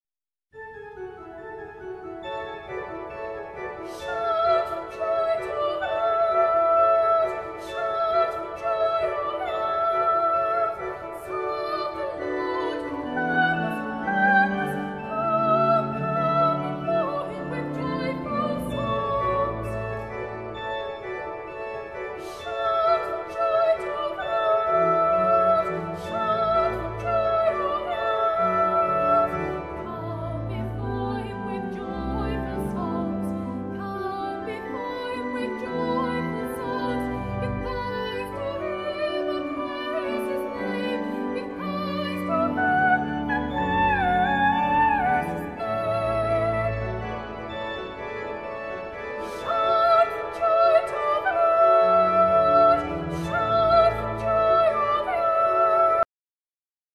Unison